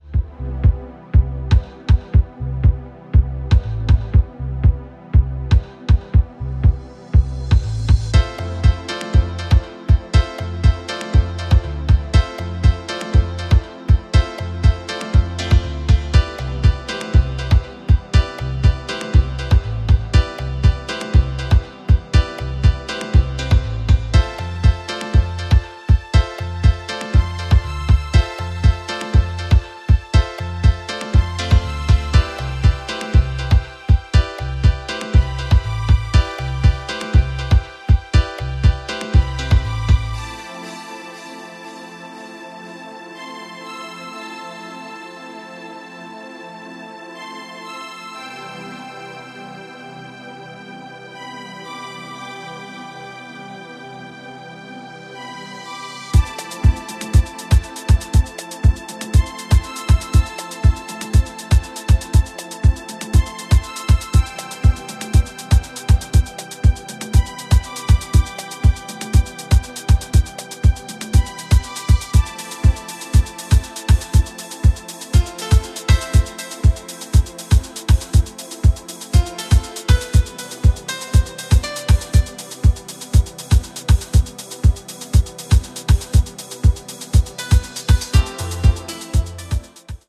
ドリーミーな90s イタリアン・ハウスに倣うディープ・ハウス群を展開しており